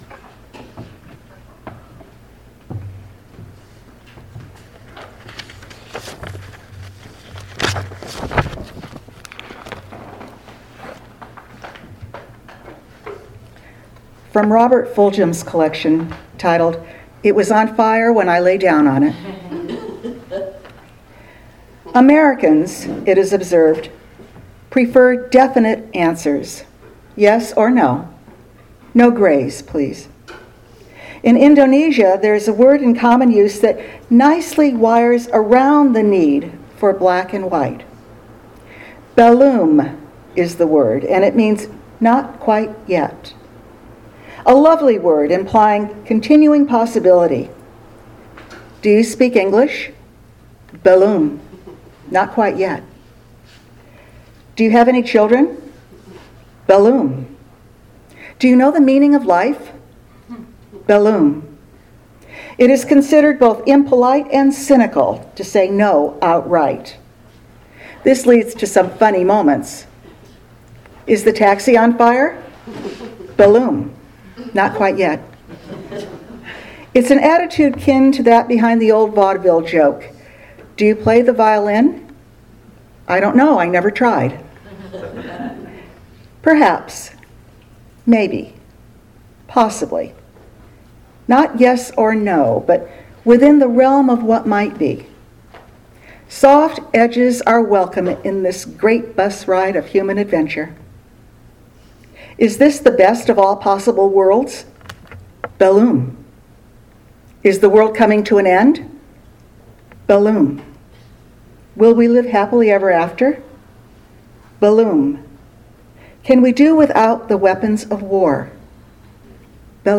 Sermon Archive | Wy'east Unitarian Universalist Congregation